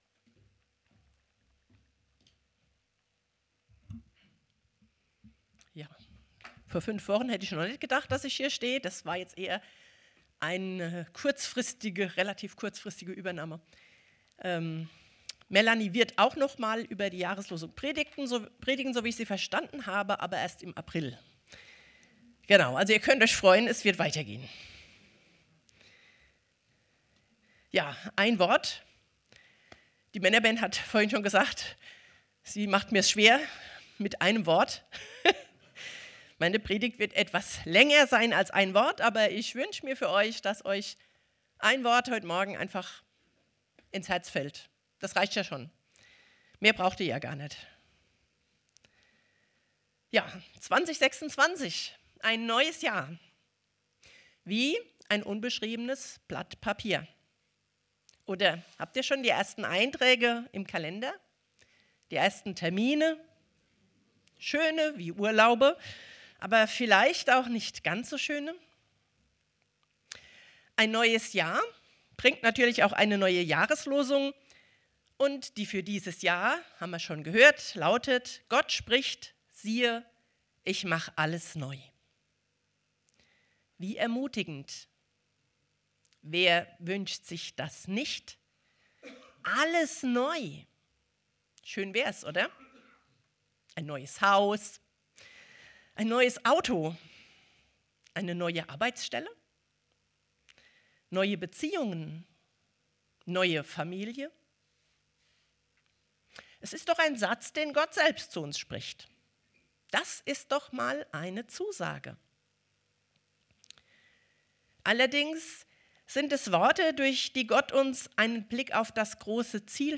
Predigt vom 04.01.2026 | Podcast der Stadtmission Alzey